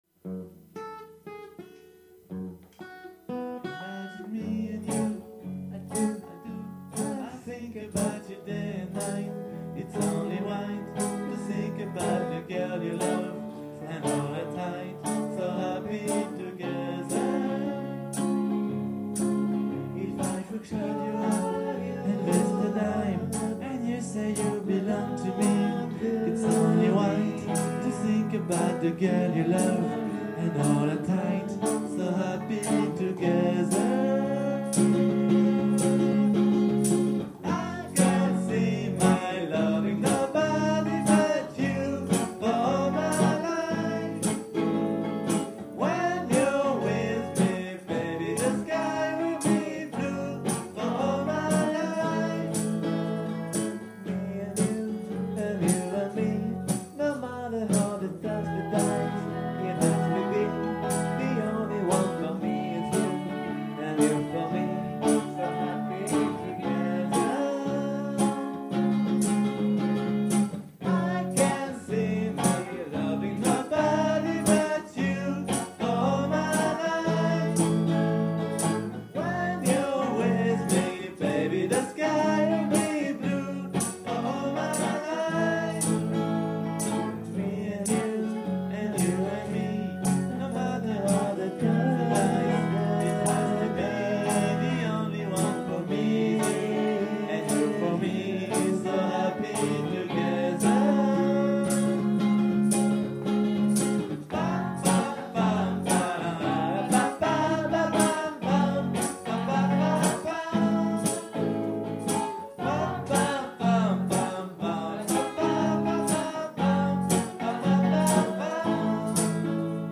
Show case à Notre-Dame de Mont :